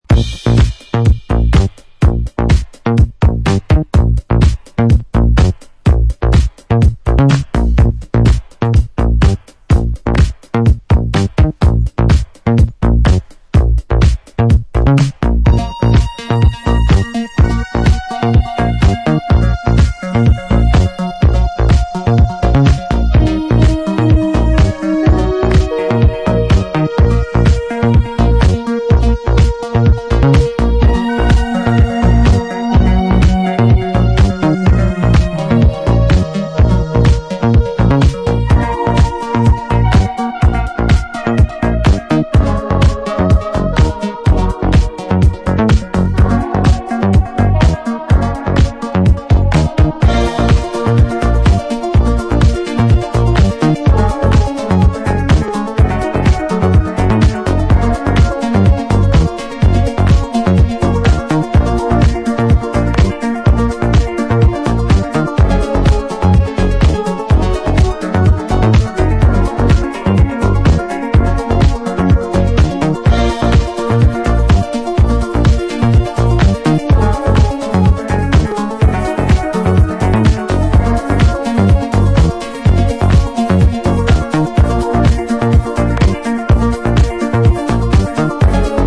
House Disco
メランコリックなアシッド・ディスコ・ダブ！